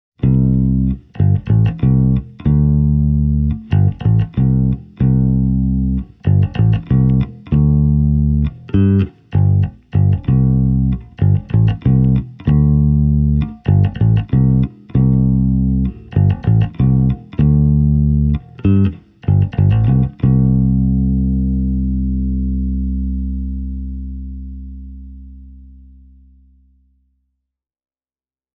All clips have been recorded with a microphone:
Jazz Bass – EQ-controls in neutral